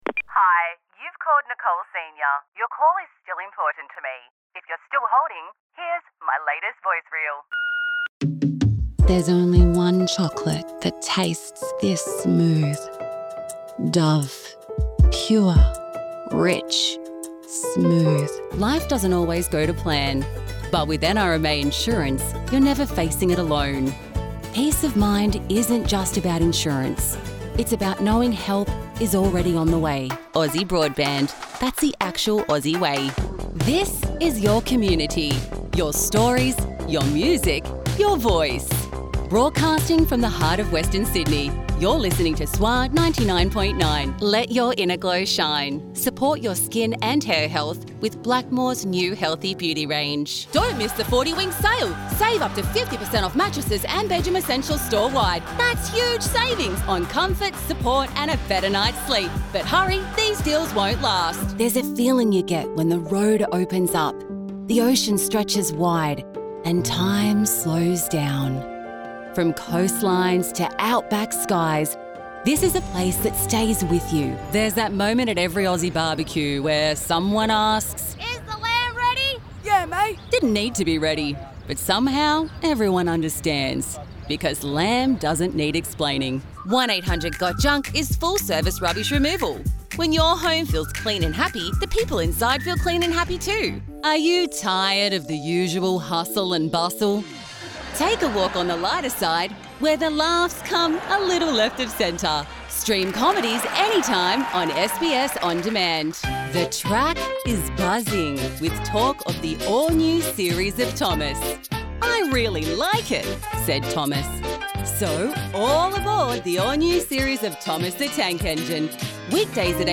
Female Voice Over Talent, Artists & Actors
Adult (30-50)